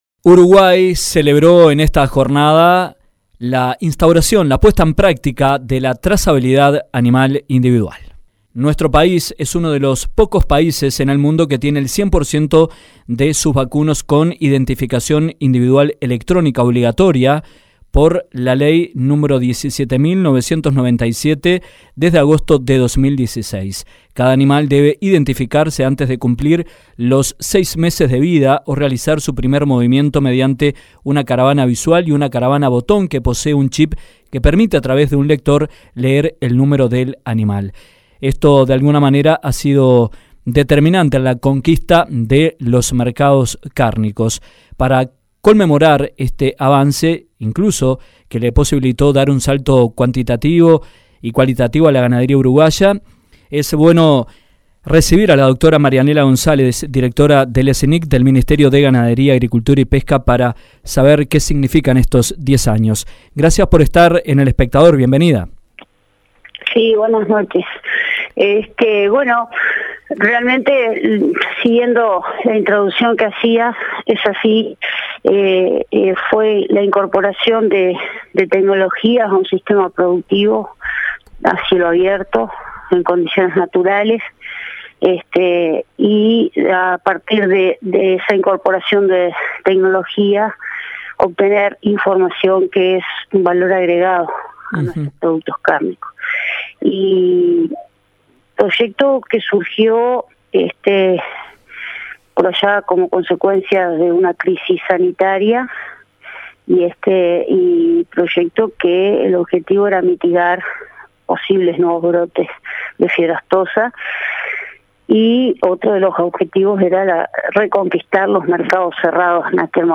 A diez años de implementado el sistema de trazabilidad, autoridades reconocieron el rol de la herramienta para ingresar 50.000 toneladas de carne a Europa por la cuota 481 a un valor promedio de 9.163 dólares la tonelada y sin aranceles, lo que significó el ingreso de US$ 300 millones adicionales desde 2011. En entrevista